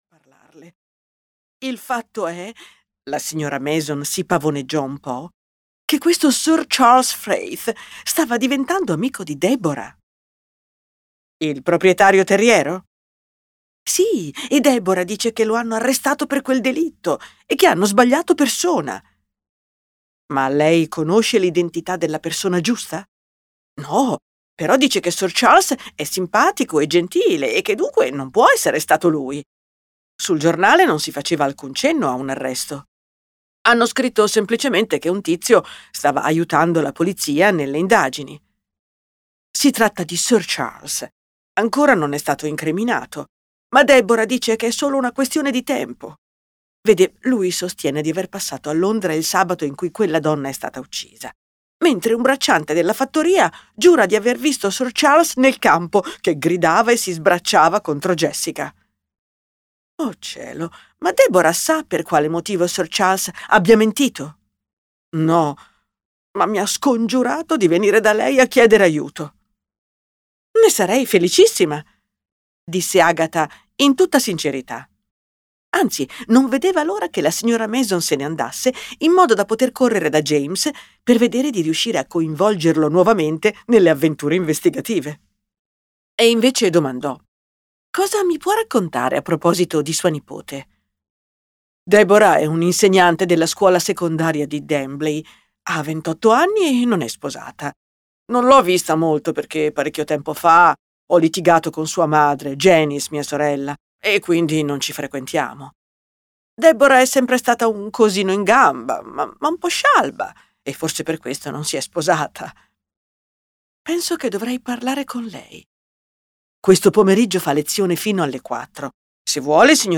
I Camminatori di Dembley" di M.C. Beaton - Audiolibro digitale - AUDIOLIBRI LIQUIDI - Il Libraio